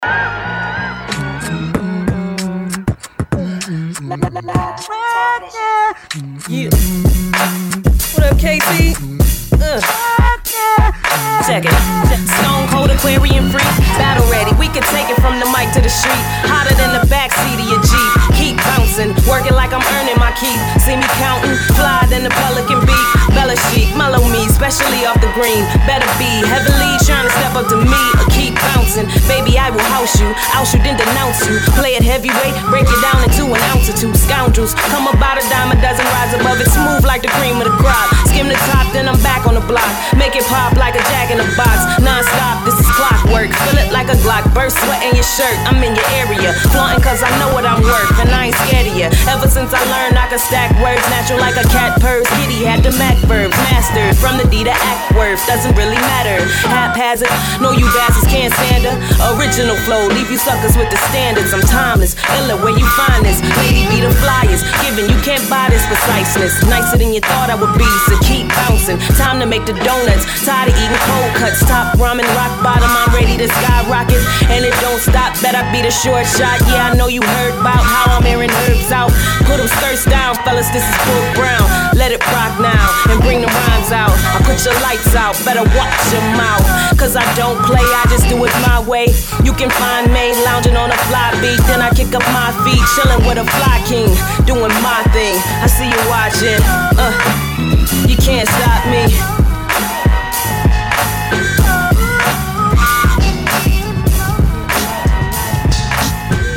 a female MC